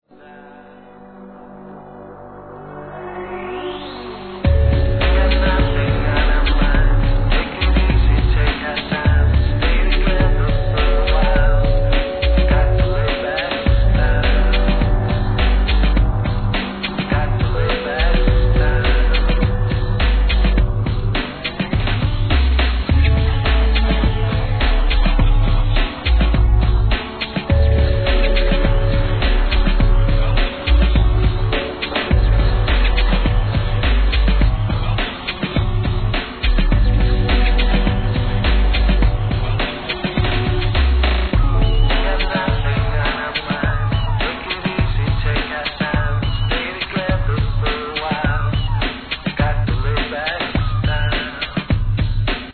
1998年、妙に落ち着かされる宇宙サウンド☆ ブレイク・ビーツ